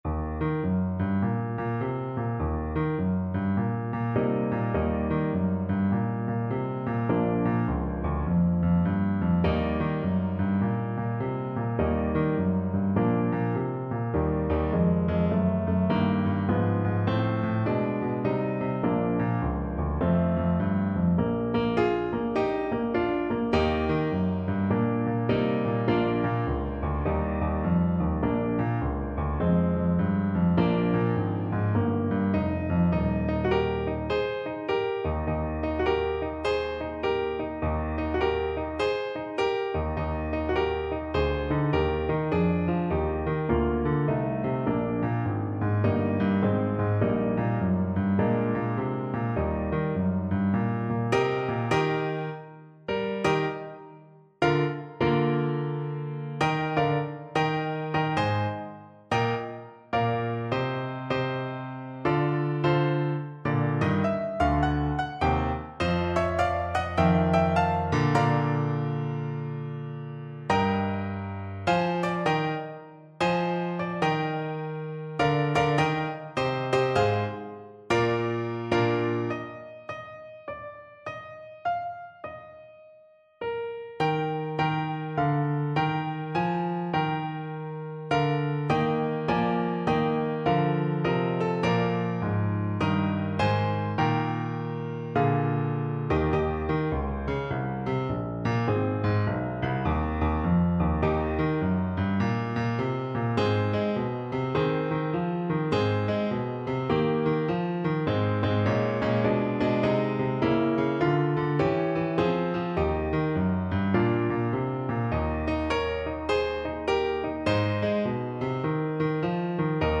Alto Saxophone
With a swing! =c.140